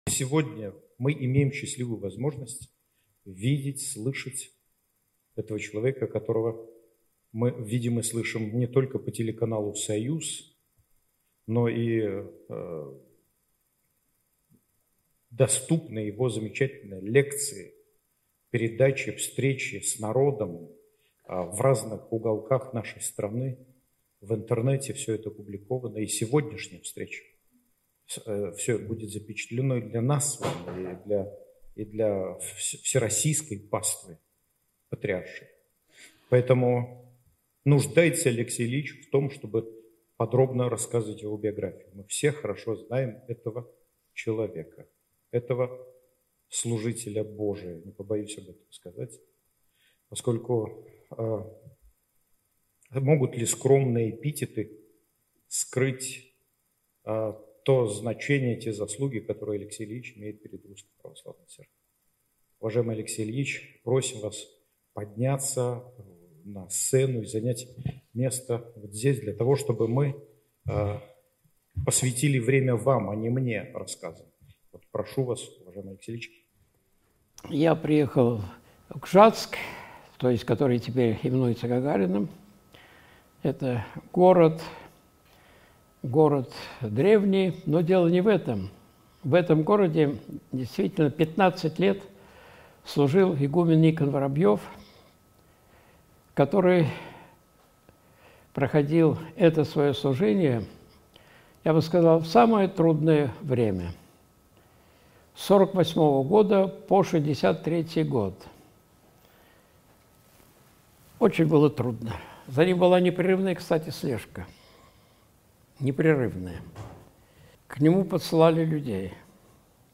Пророчество, которое сбывается на наших глазах (Смоленская семинария, 07.09.2024)
Видеолекции протоиерея Алексея Осипова